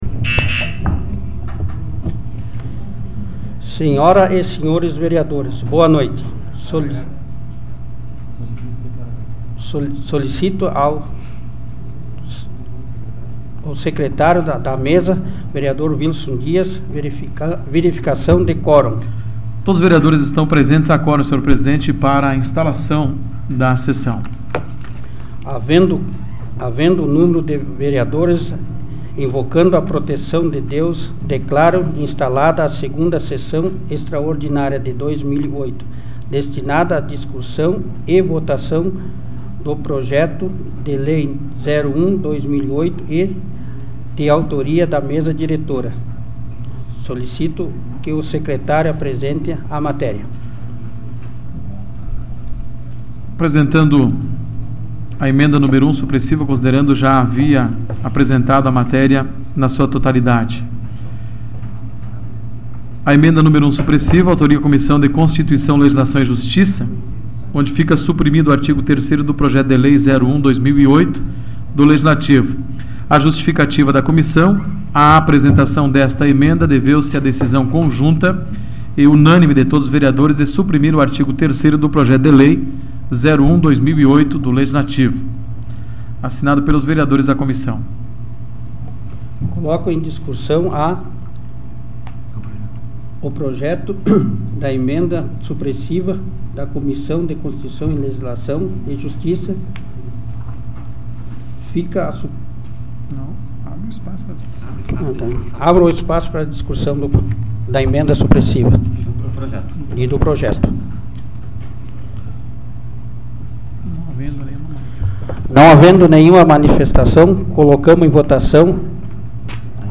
Áudio da 45ª Sessão Plenária Extraordinária da 12ª Legislatura, de 29 de janeiro de 2008